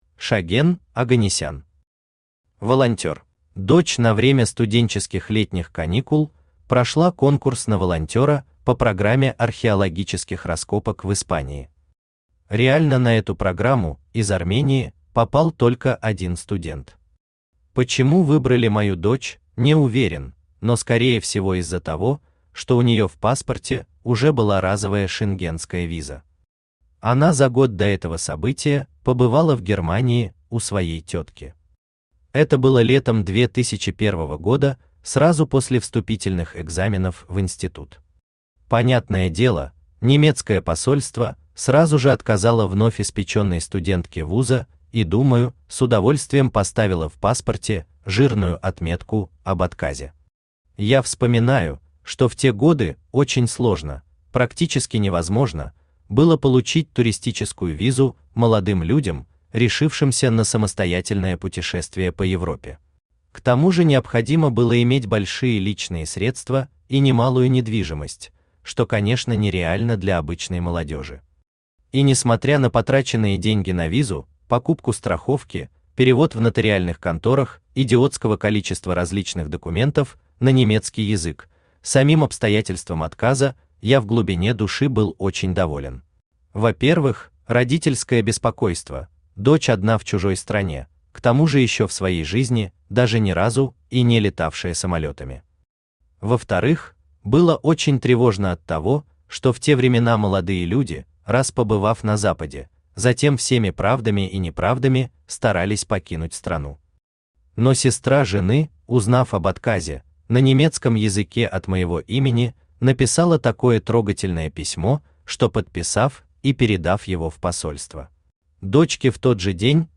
Аудиокнига Волонтёр | Библиотека аудиокниг
Aудиокнига Волонтёр Автор Шаген Оганнисян Читает аудиокнигу Авточтец ЛитРес.